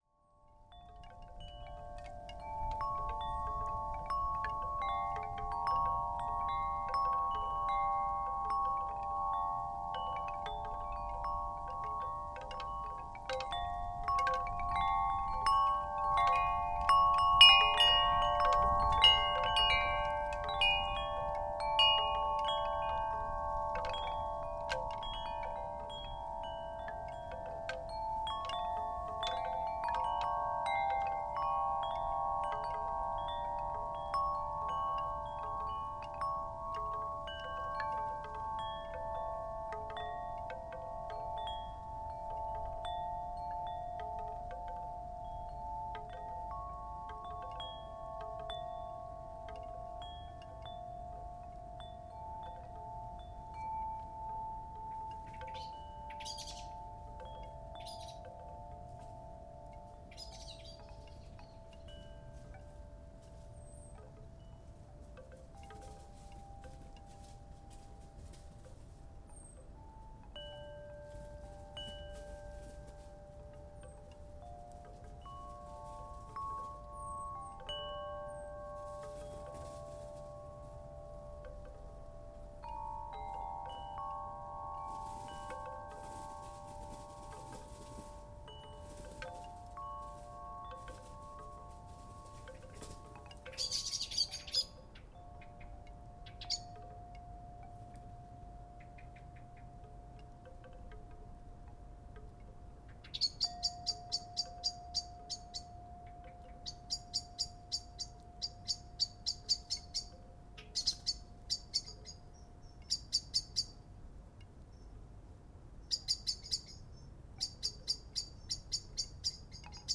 Wind Chimes, Teign Gorge near Castle Drogo - Gypsy Mezzo - Bamboo - excerpt
alarm-call bamboo bird blackbird Castle-Drogo chimes Devon England sound effect free sound royalty free Sound Effects